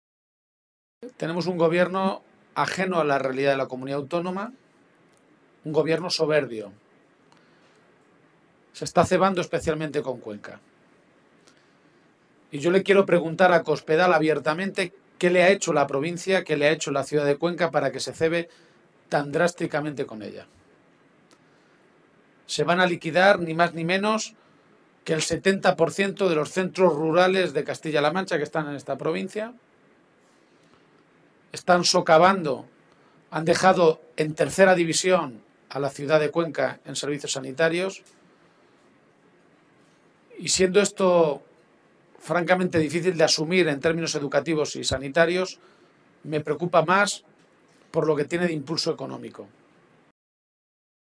Poco antes del inicio de esa reunión, el secretario general regional, Emiliano García-Page, ha comparecido ante los medios de comunicación para anunciar la aprobación de una resolución que ha llamado “La Resolución de Cuenca”.